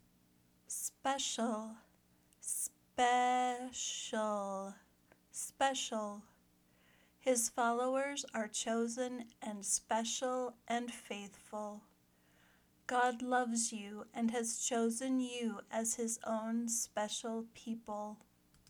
/ˈspe ʃl/ (adjective)